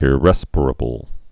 (ĭ-rĕspər-ə-bəl, ĭrĭ-spīr-)